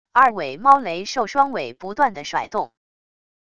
二尾猫雷兽双尾不断的甩动wav音频